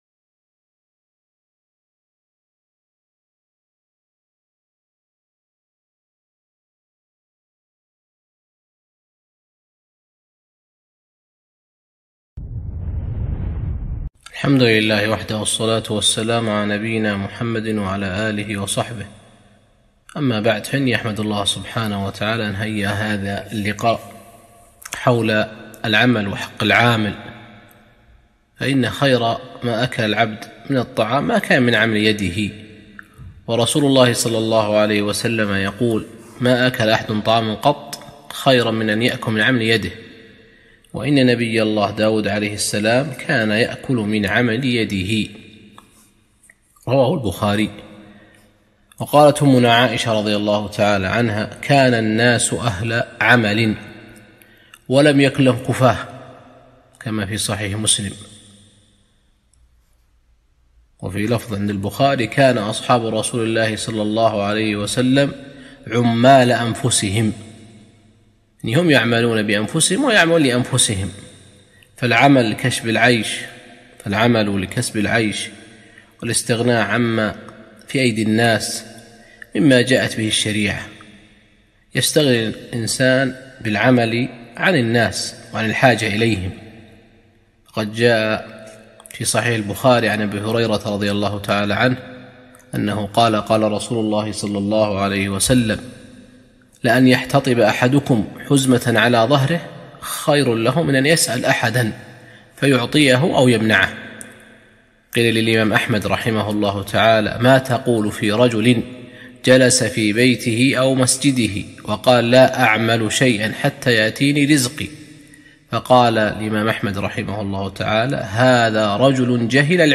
كلمة - التحذير من ظلم العمال والاعتداء عليهم